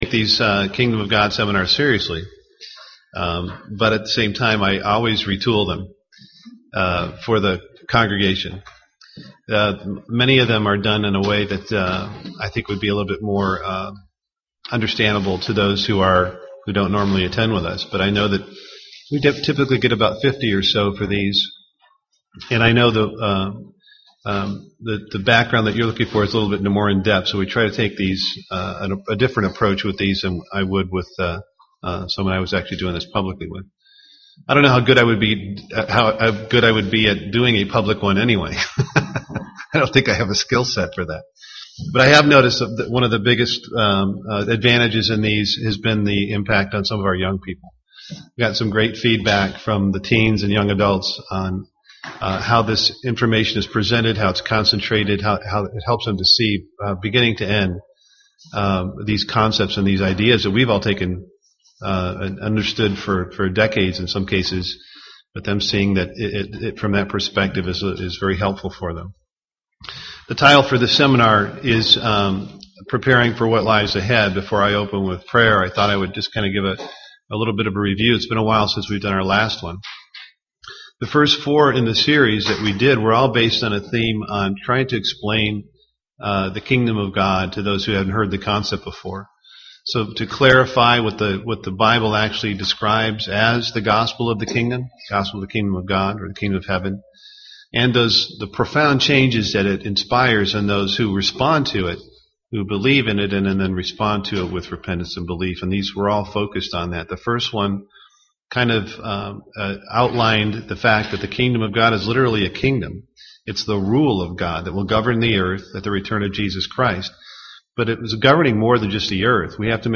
Why does world peace continually elude humanity? And the most important crisis of our time is coming. Learn more about the way of peace in this Kingdom of God seminar.